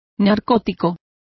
Also find out how narcotica is pronounced correctly.